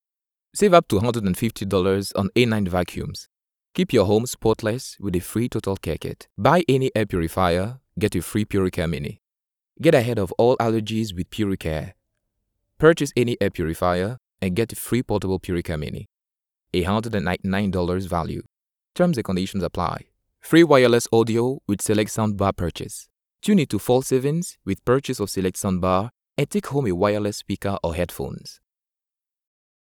Démo commerciale
ChaleureuxDe la conversationÉnergique